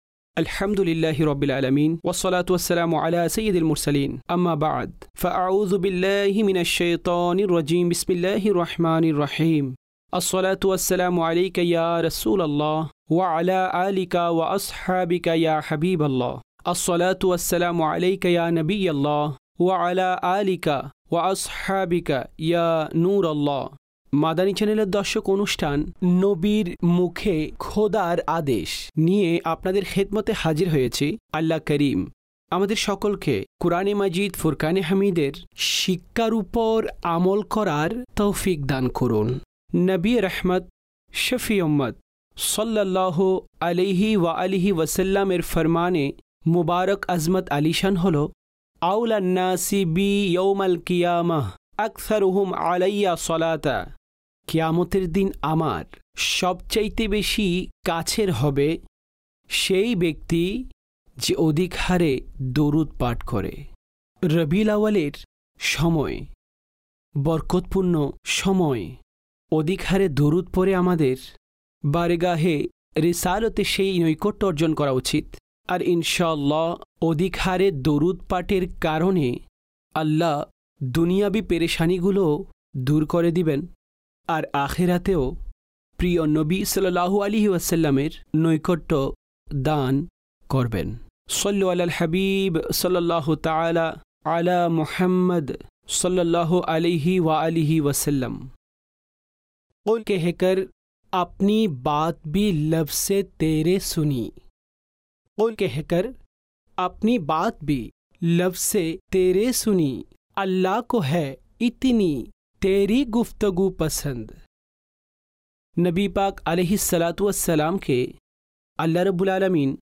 (বাংলায় ডাবিংকৃত)